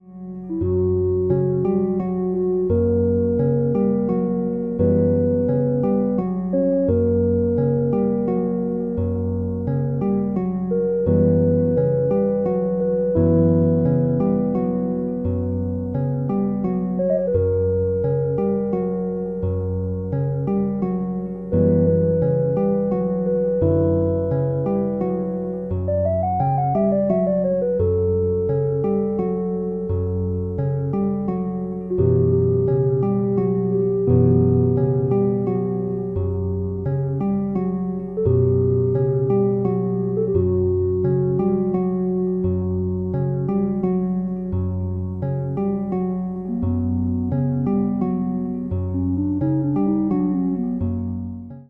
F# minor